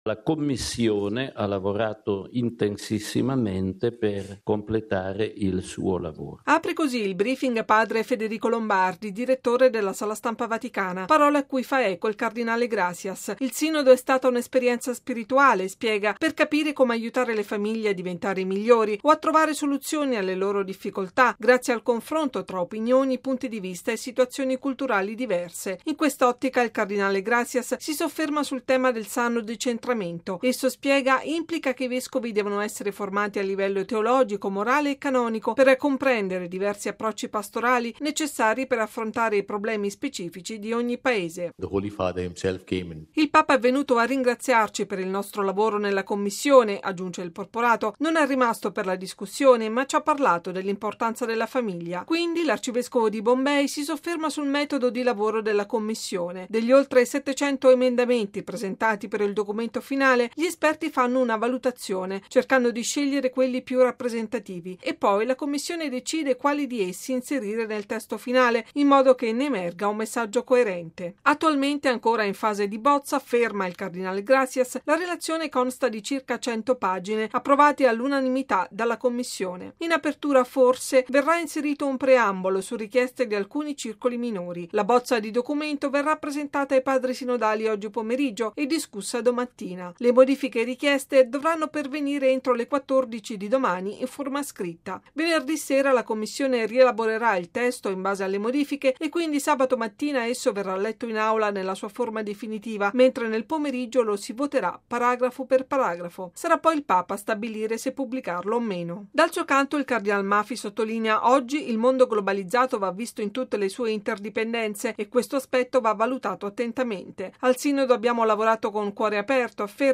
Stamani, intanto, consueto briefing in Sala Stampa vaticana.